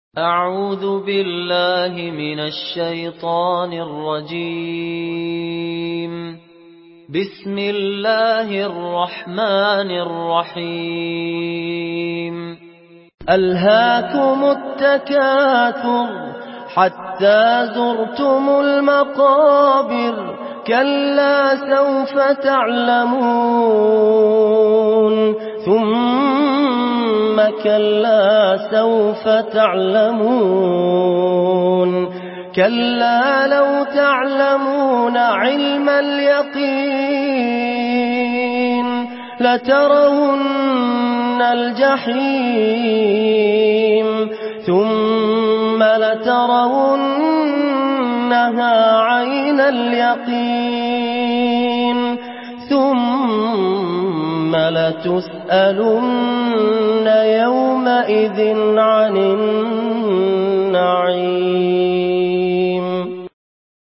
سورة التكاثر MP3 بصوت فهد الكندري برواية حفص
مرتل